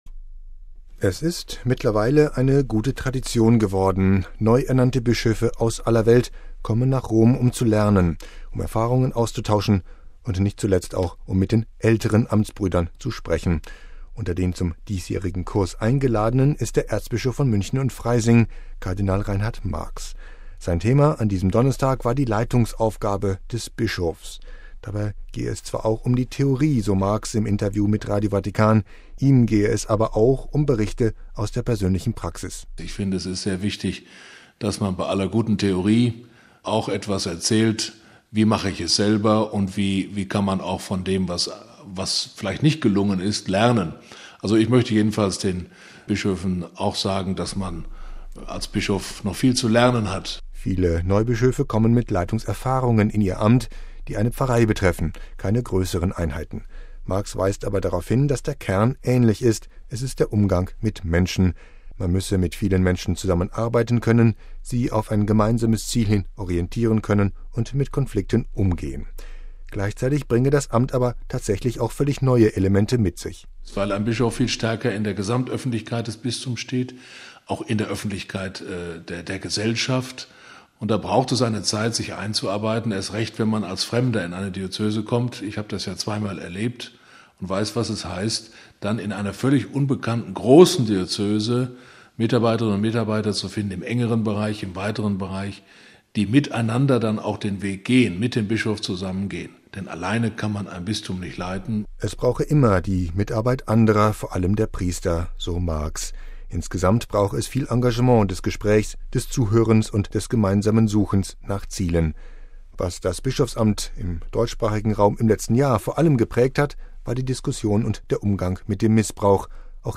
Sein Thema an diesem Donnerstag war die Leitungsaufgabe des Bischofs. Dabei geht es zwar auch um die Theorie, so Marx im Interview mit Radio Vatikan.